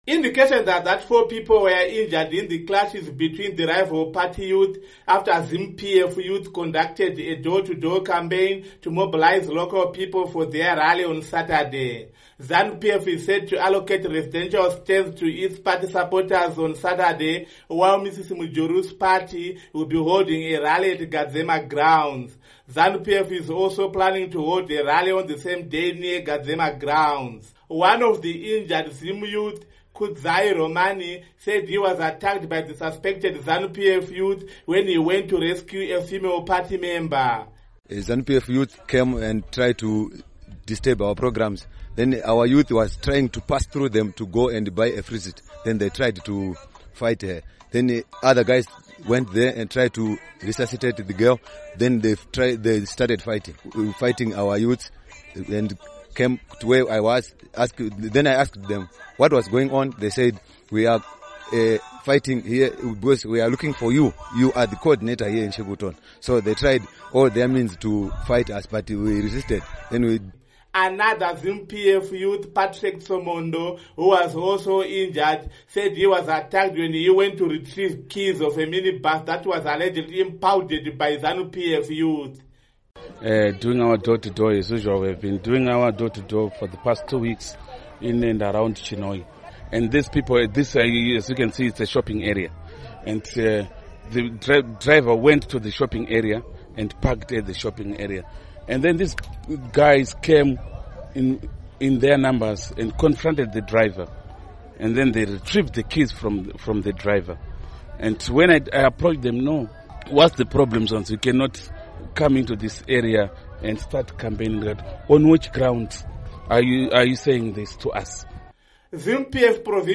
Report on Political Violence